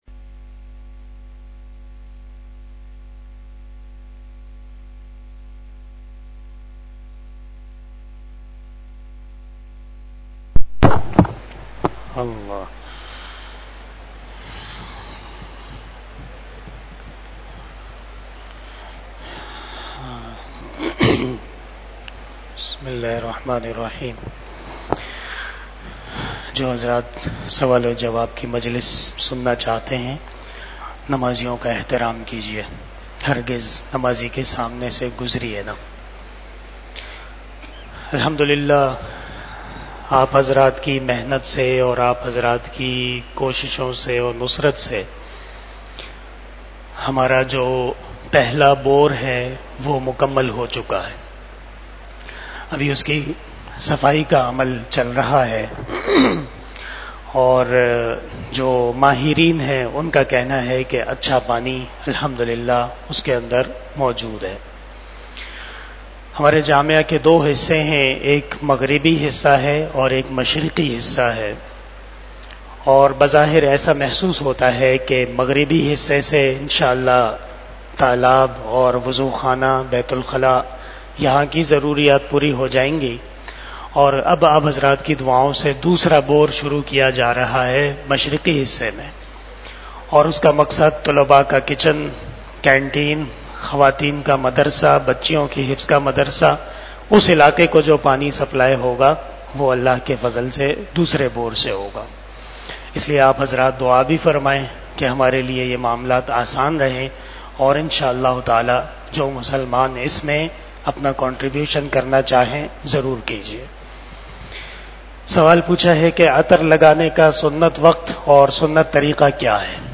بیان جمعۃ المبارک 25 صفر المظفر ۱٤٤٦ھ بمطابق 30 اگست 2024ء